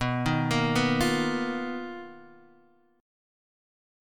BM7b5 chord